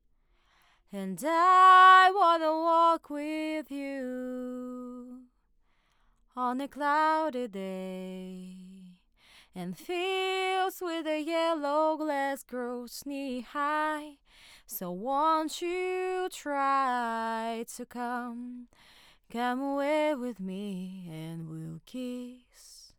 Вот, записано одновременно с одинаковыми настройками, у октавы убрал внутреннюю сетку одну.
Но, сильной разницы в звучании при таком прямом сравнении не нашел:cool:.
Роде как будто чётче и ярче в верхней середине,Октава вроде бы яркий мик,этой яркости что то не слышно,хотя можно добавить верхов,низ порезать,непонятно,может действительно что менять надо из деталей в Октаве.